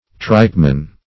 tripeman - definition of tripeman - synonyms, pronunciation, spelling from Free Dictionary Search Result for " tripeman" : The Collaborative International Dictionary of English v.0.48: Tripeman \Tripe"man\, n.; pl. -men . A man who prepares or sells tripe.